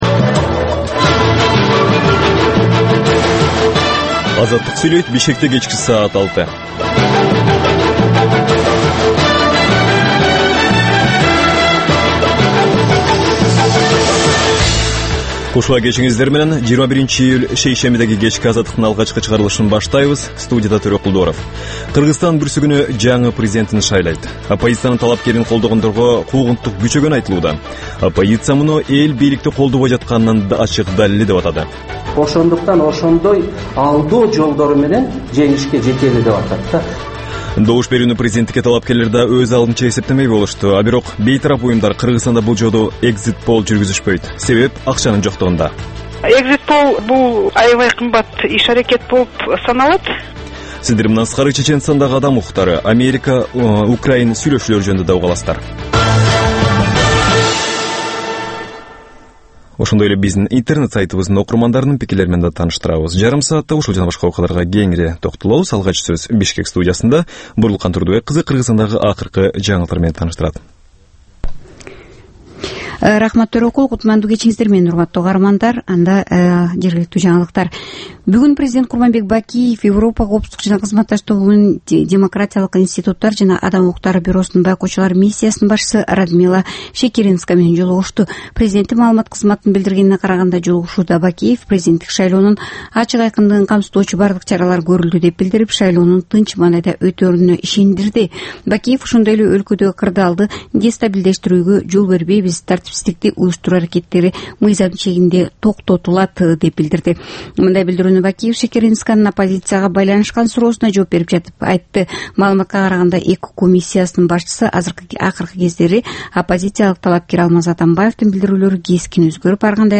"Азаттык үналгысынын" бул кечки алгачкы берүүсү жергиликтүү жана эл аралык кабарлардан, репортаж, маек, баян жана башка берүүлөрдөн турат. Бул үналгы берүү ар күнү Бишкек убактысы боюнча саат 18:00ден 18:30га чейин обого түз чыгат.